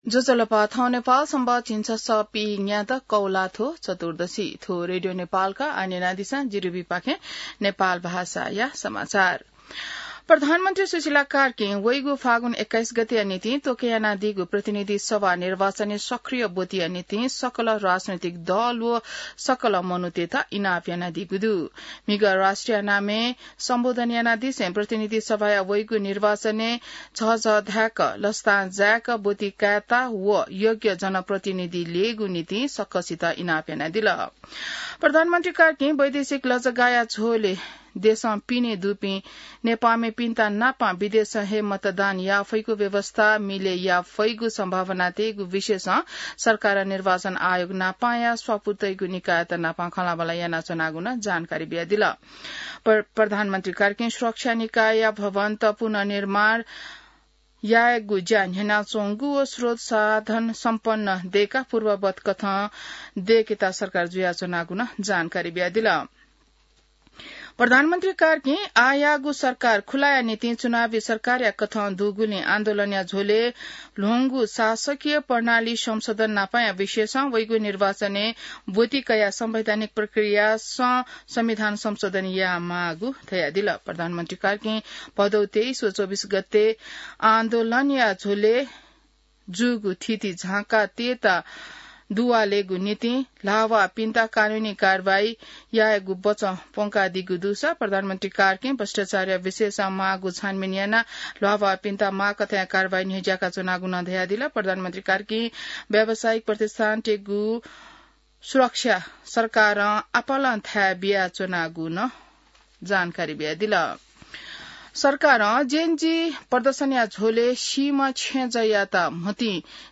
An online outlet of Nepal's national radio broadcaster
नेपाल भाषामा समाचार : १० असोज , २०८२